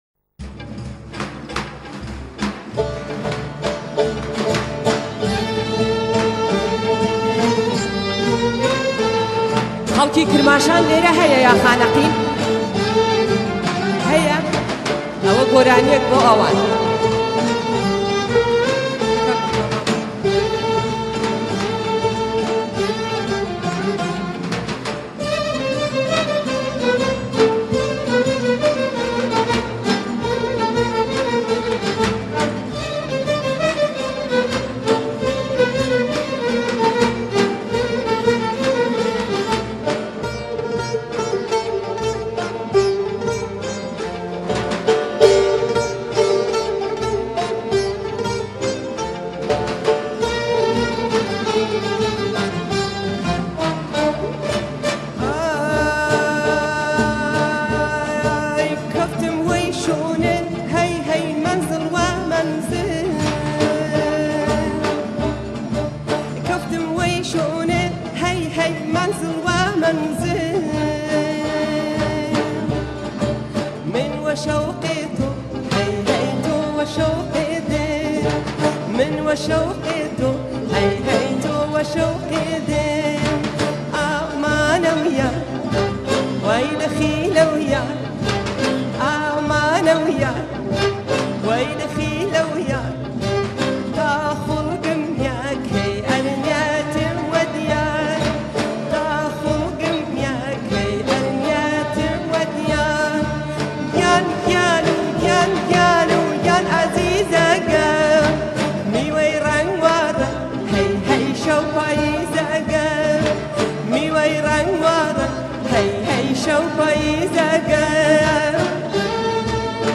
آهنگ کردی جدید
آهنگ فولکلور